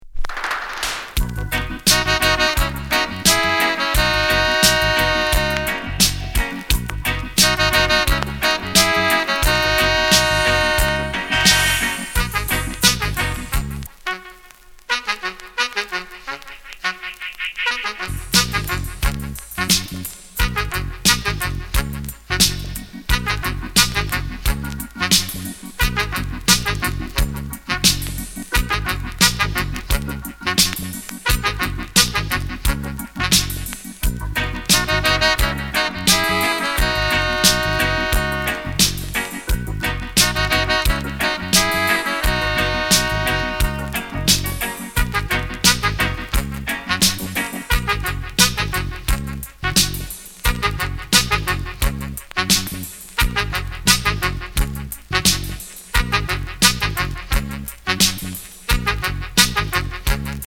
Genre: Reggae
B: Instrumental Version